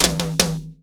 TOM     1C.wav